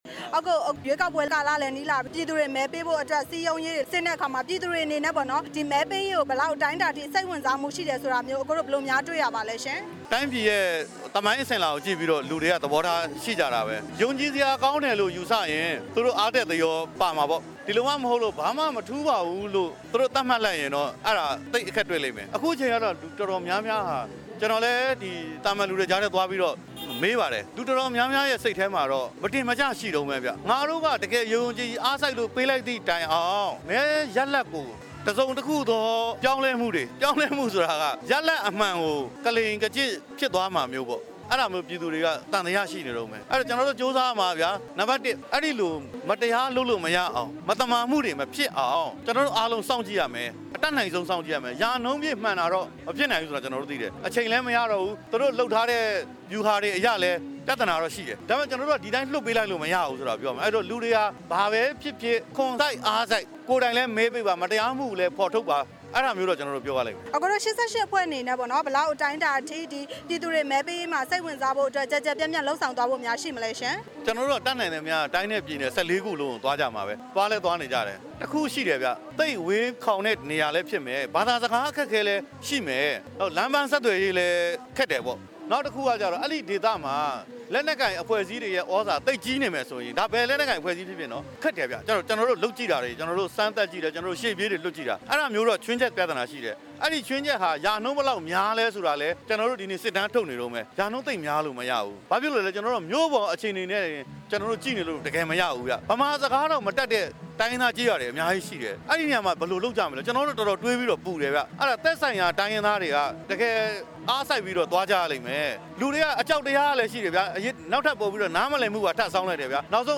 မော်လမြိုင်မြို့ ရွေးကောက်ပွဲအသိပညာပေးပွဲအကြောင်း ကိုမင်းကိုနိုင်နဲ့ မေးမြန်းချက်